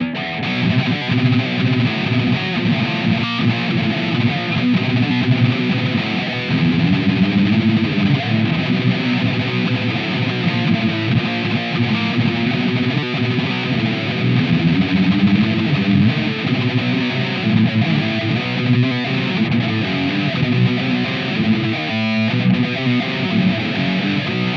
a "ripping" tone.